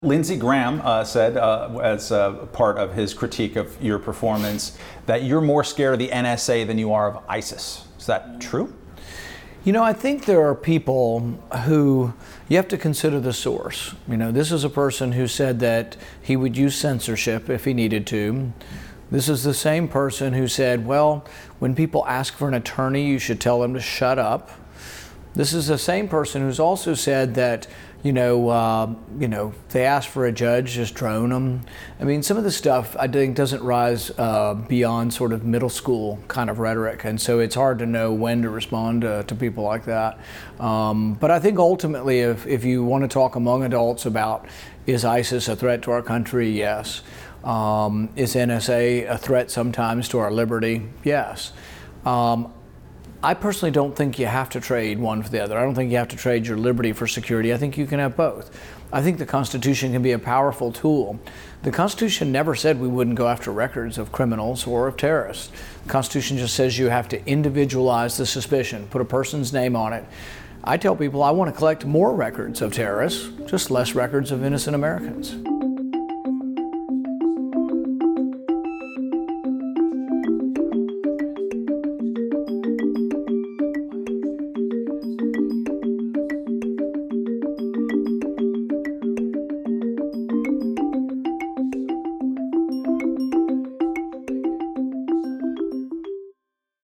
This is a rush transcript.